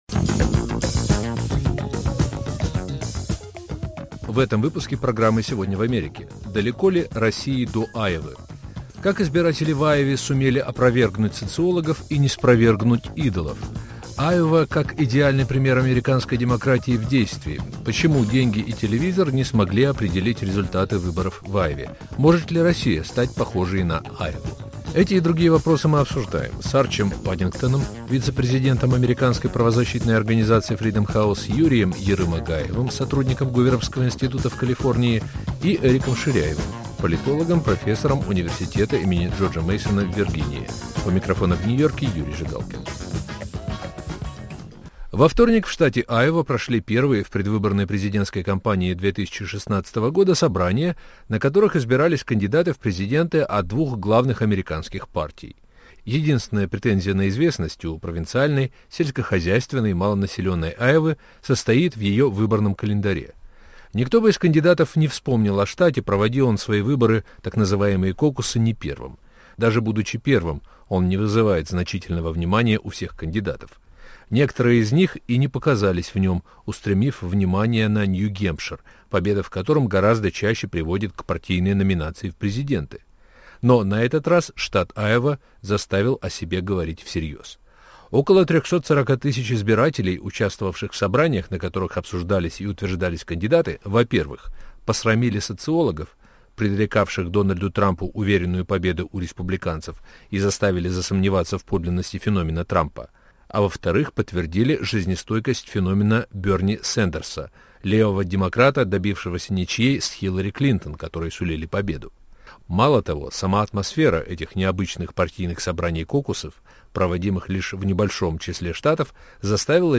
обсуждают американские эксперты